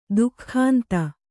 ♪ duhkhānta